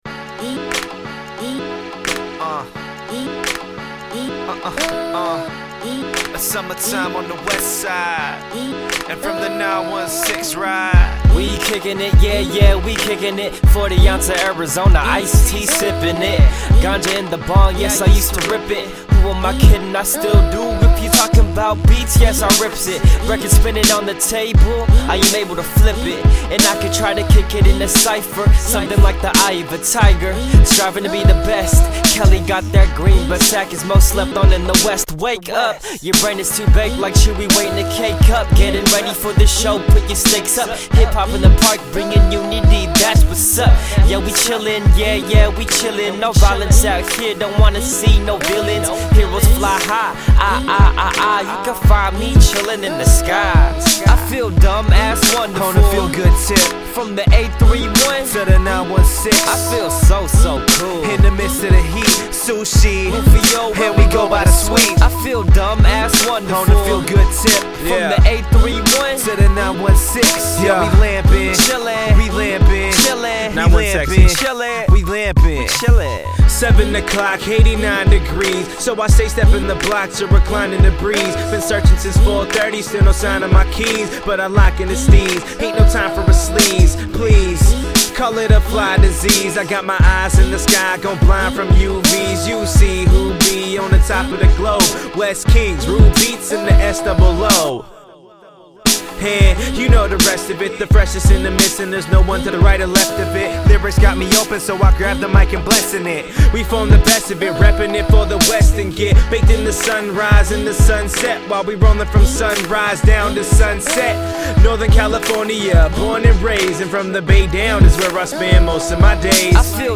The beats on the EP are BANGING! Seriously great breats.